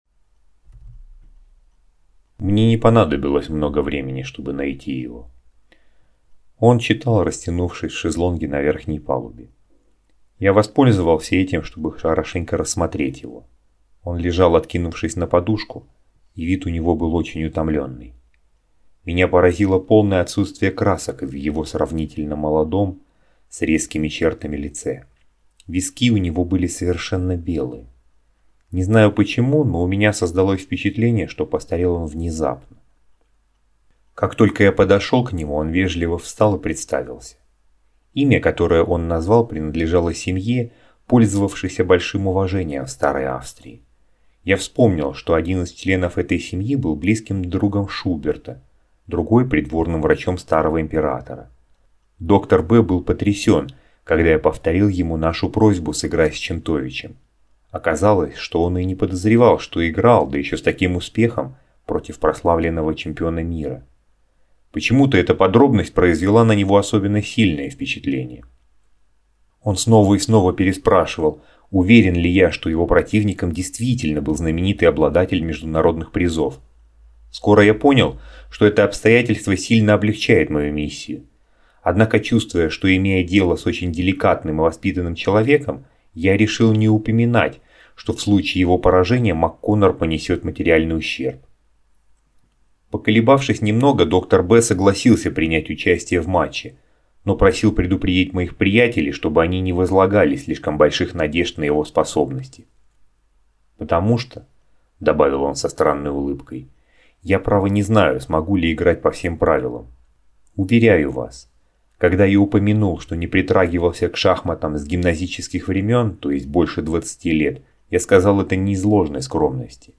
Аудиокнига Шахматная новелла
Качество озвучивания весьма высокое.